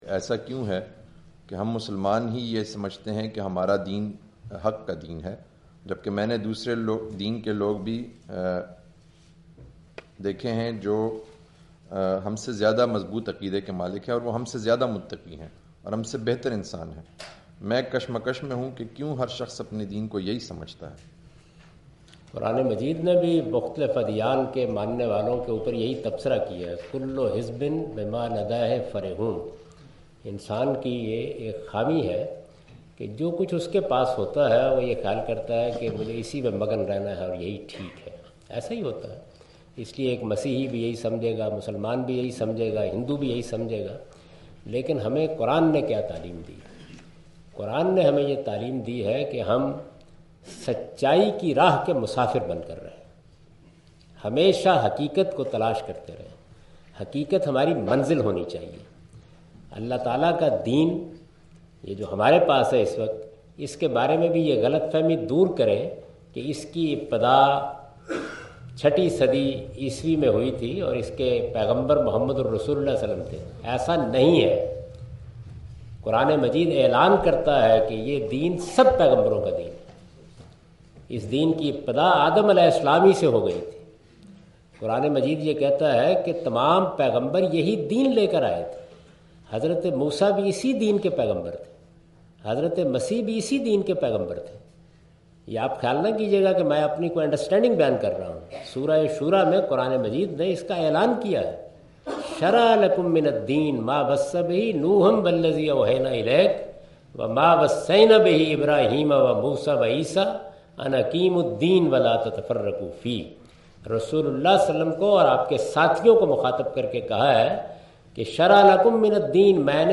Javed Ahmad Ghamidi answer the question about "Is Islam the True Religion?" asked by audience during his visit to Queen Mary University of London UK in March 13, 2016.
جاوید احمد صاحب غامدی اپنے دورہ برطانیہ 2016 کےدوران کوئین میری یونیورسٹی اف لندن میں "کیا اسلام ہی دینِ حق ہے؟" سے متعلق ایک سوال کا جواب دے رہے ہیں۔